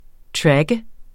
tracke verbum Bøjning -r, -de, -t Udtale [ ˈtɹagə ] Oprindelse kendt fra 1997 fra engelsk track , 'følge, spore' Betydninger 1.